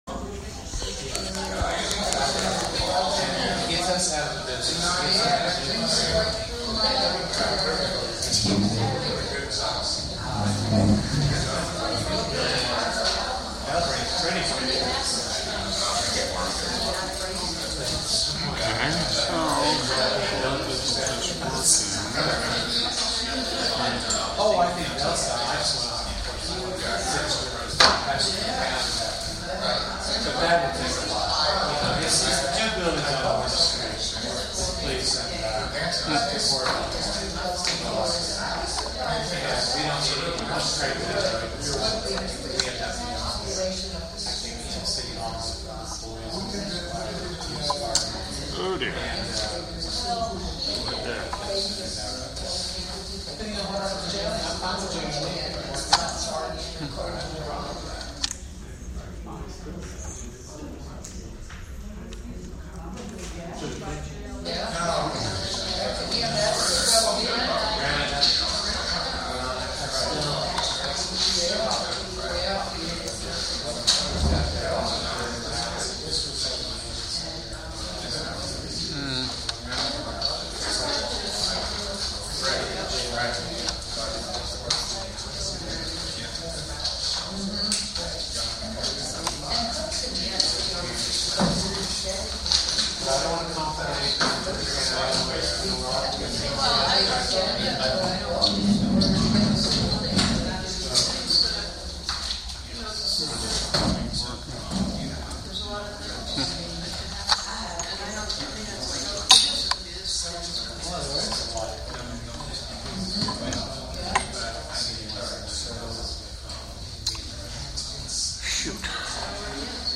Monday, August 10, 2018 Hudson Common Council meeting.